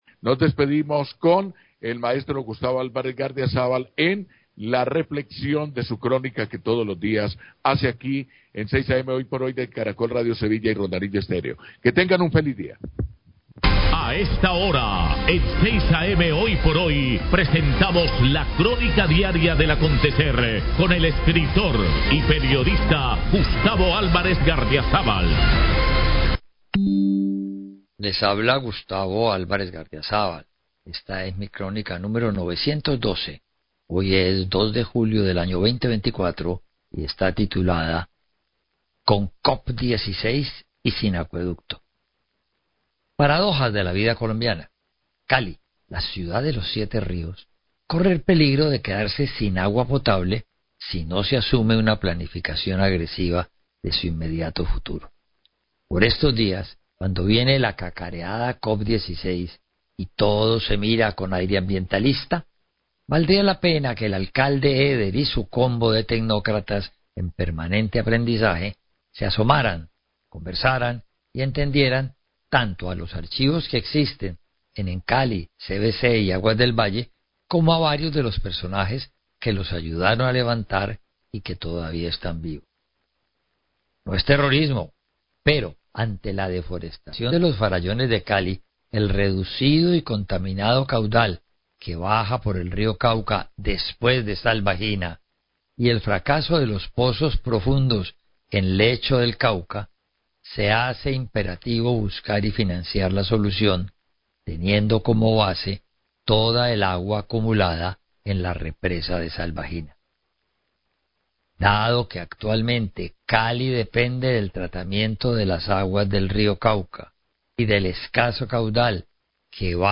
Radio
columna de opinión